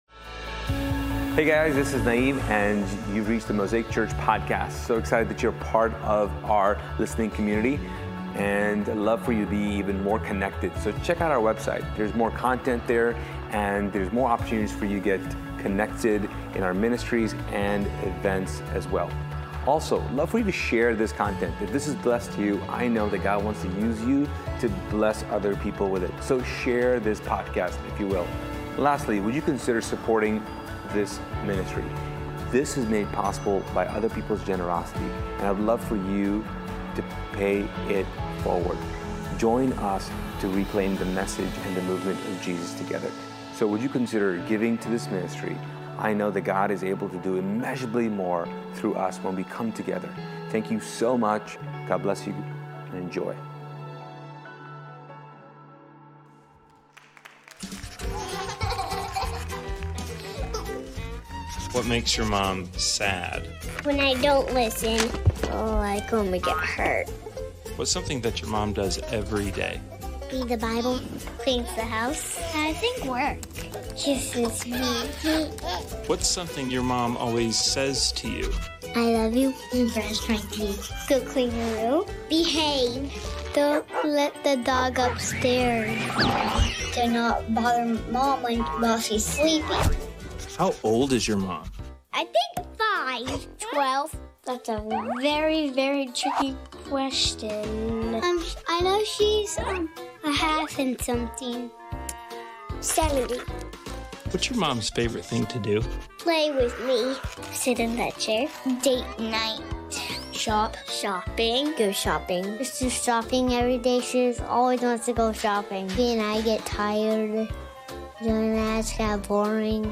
This year for Mother's Day, we capitalized on the diversity of Mosaic to learn from 5 different women with 5 different backgrounds.
It takes practice to decenter ourselves and get curious about the hidden lives of other people and this round-table discussion will help you open your mind to seeing things from different perspectives.